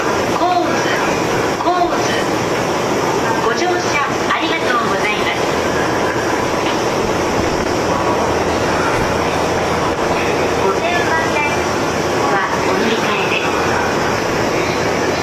到着放送